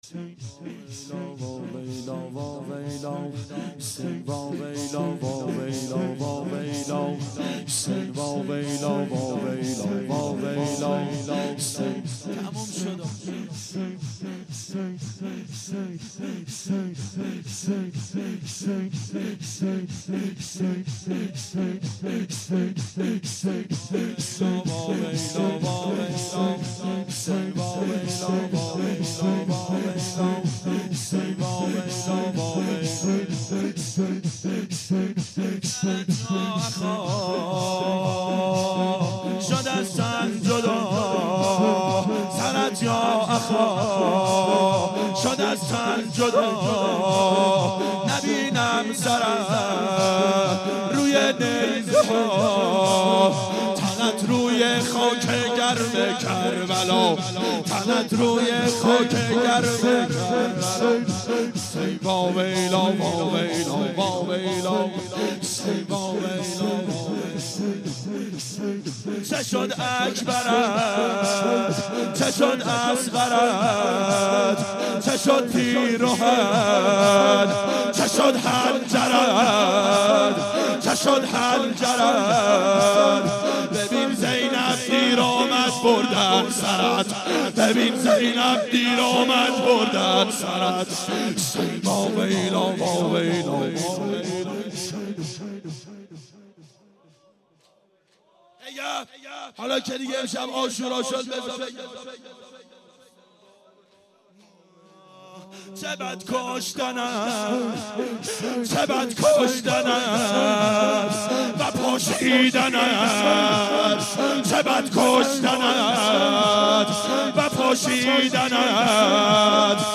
دهه اول صفر سال 1392 هیئت شیفتگان حضرت رقیه سلام الله علیها